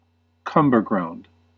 Ääntäminen
IPA : /ˈkʌm.bə.ɡɹaʊnd/ US : IPA : /ˈkʌm.bɚ.ɡɹaʊnd/